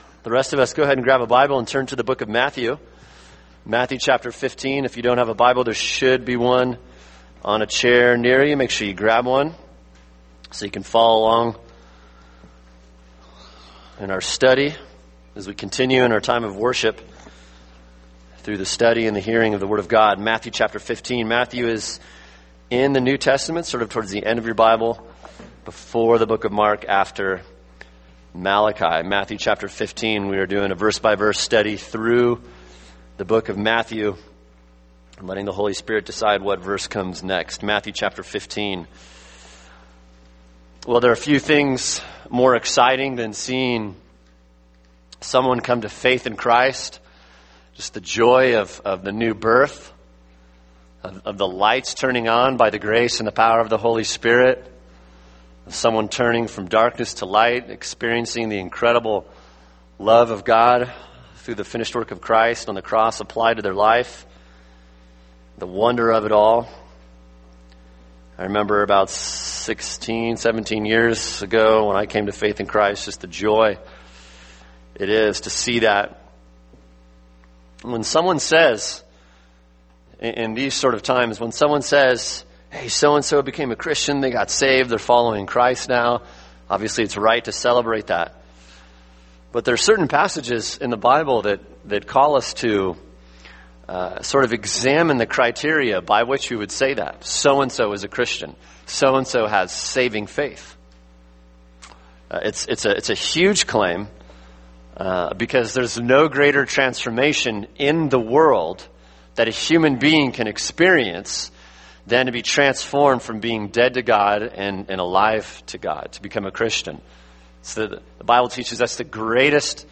[sermon] Matthew 15:21-28 – Marks of Saving Faith | Cornerstone Church - Jackson Hole